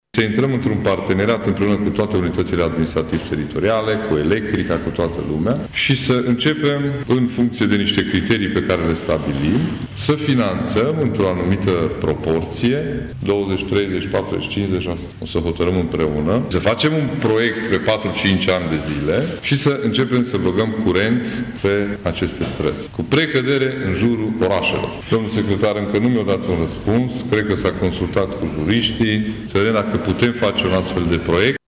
Astfel, CJ Mureș ar urma să intre într-un pateneriat cu compania Electrica SA, a explicat Ciprian Dobre: